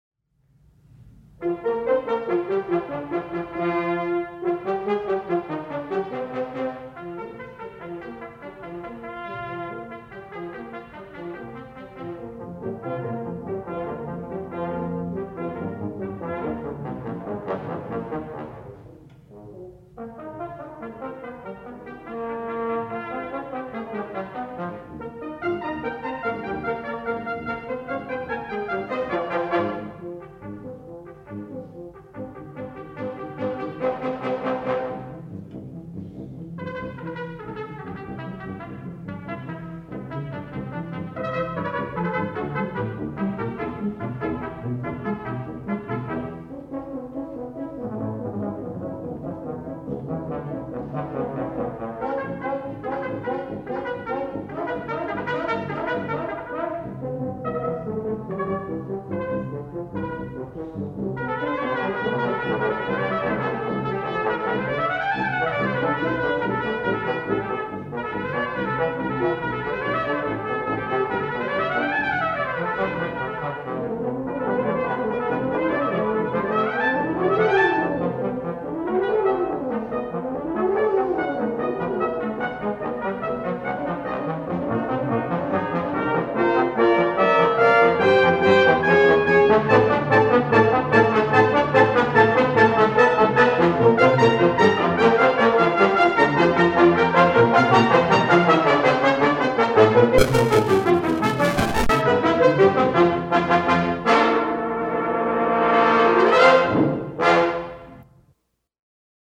Concert Performance October 7, 1973
Audience nearly filled the house.
using a half-track, 10” reel-to-reel Ampex tape recorder
Armstrong Auditorium, Sunday at 4:00 PM
Rondo